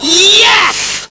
Amiga 8-bit Sampled Voice